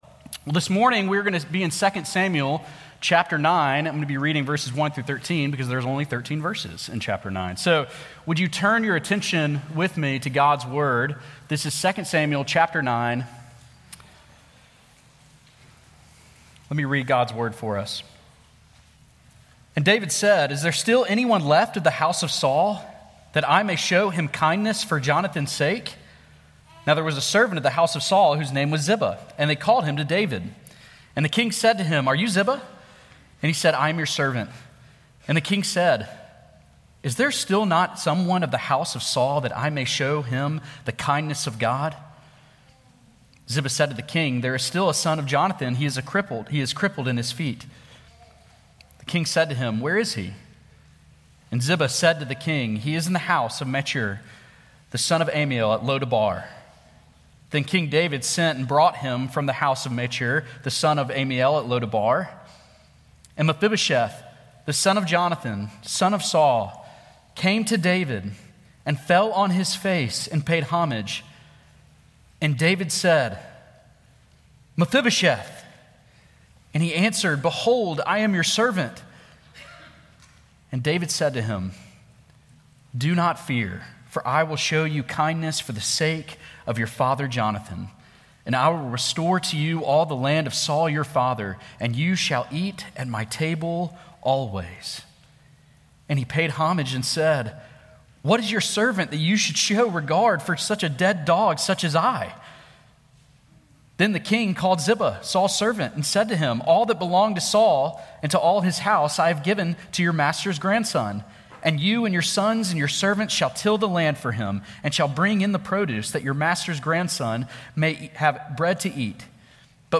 Scripture Reference: 2 Samuel 9:1-13 Sermon Points: The Person Covenant Kindness Embraces (vv. 1-8) The Power Covenant Kindness Exercises (vv. 9-13)